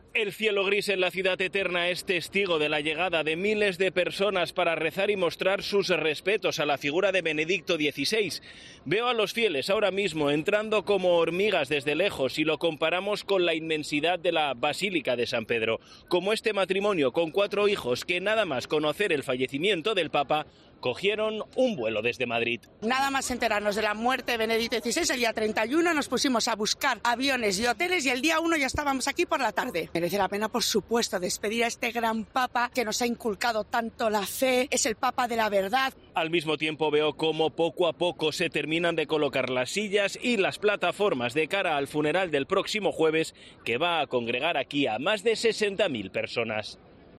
Desde la Plaza de San Pedro, uno de los enviados especiales de los servicios informativos de la Cadena COPE es testigo de la llegada de cientos de personas cuando comienza a amanecer en Roma.